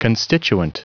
Prononciation du mot constituent en anglais (fichier audio)
Prononciation du mot : constituent